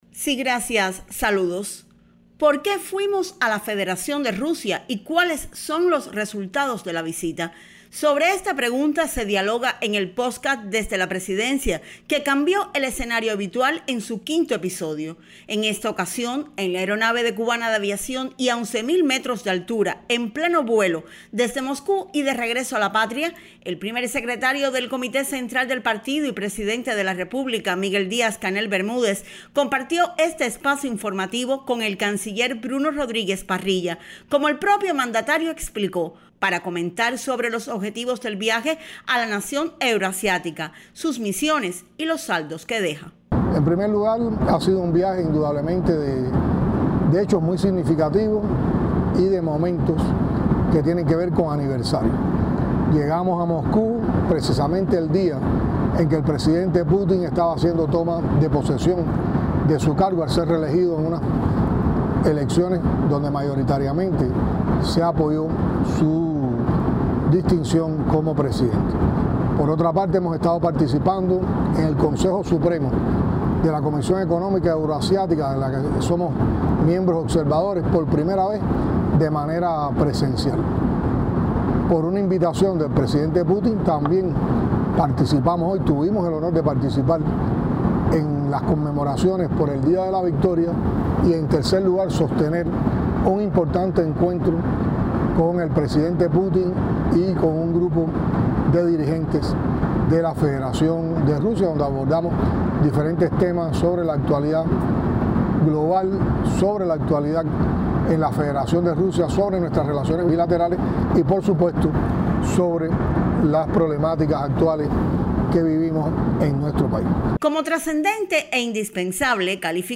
Conversa con el miembro del Buró Político y ministro de Relaciones Exteriores, Bruno Rodríguez Parrilla, en la aeronave que los conducía de regreso a la patria